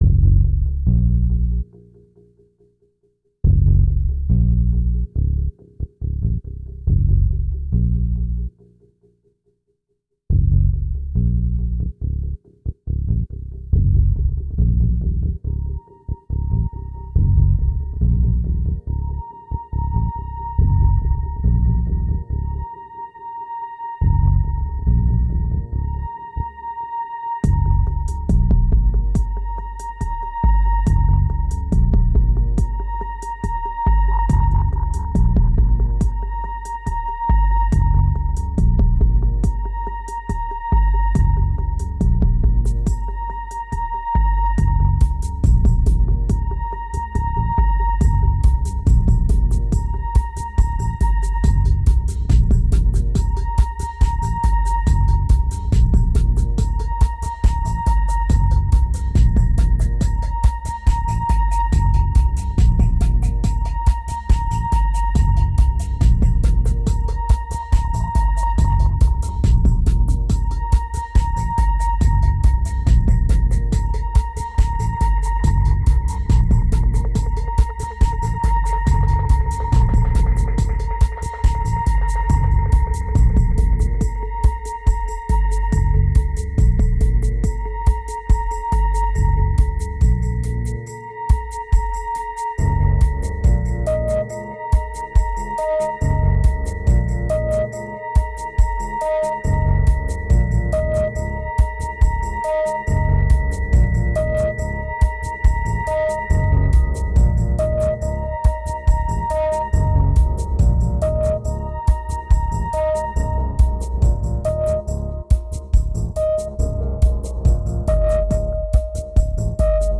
2079📈 - -45%🤔 - 70BPM🔊 - 2010-07-20📅 - -314🌟